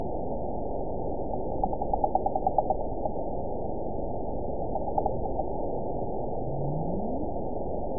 event 911564 date 03/03/22 time 15:49:49 GMT (3 years, 2 months ago) score 9.25 location TSS-AB05 detected by nrw target species NRW annotations +NRW Spectrogram: Frequency (kHz) vs. Time (s) audio not available .wav